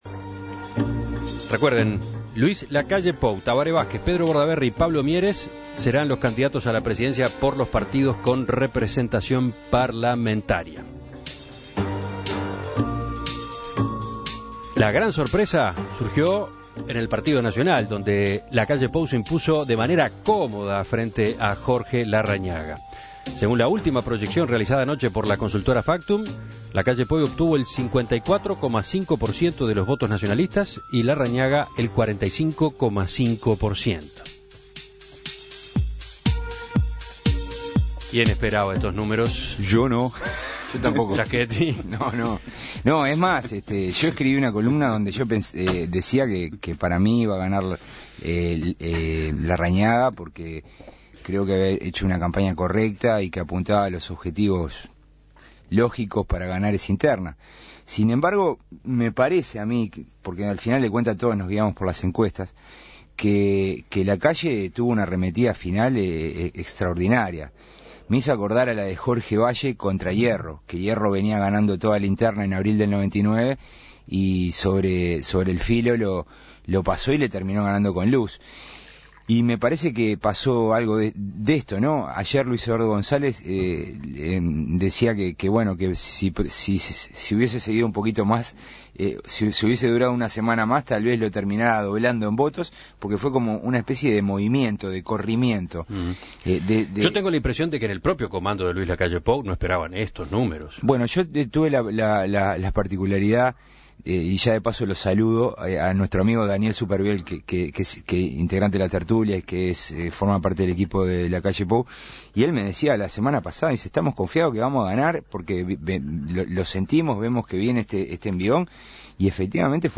Mesa de análisis